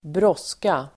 Uttal: [²br'ås:ka]